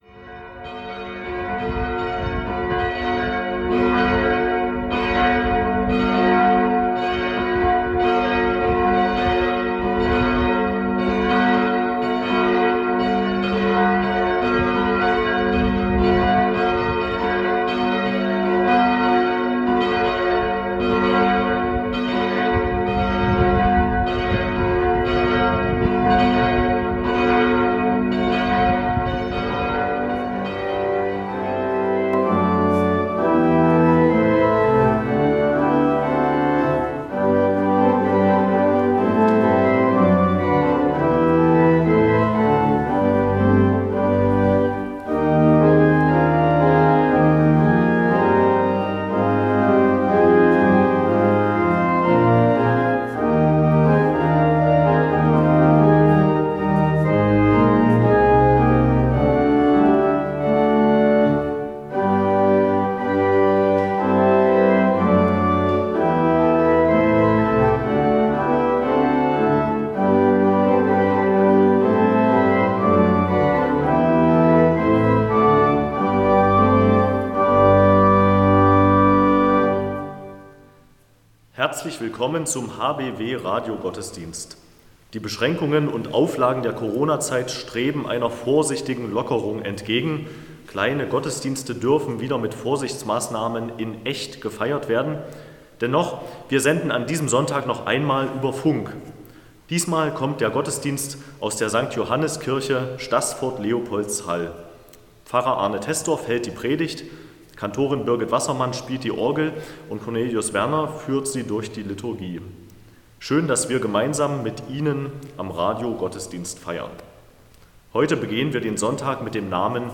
Radiogottesdienst
Der Evangelische Kirchenkreis Bernburg hat wieder einen Radiogottesdienst für Sie aufgezeichnet, diesmal in der Sankt-Johannis-Kirche in Staßfurt-Leopoldshall.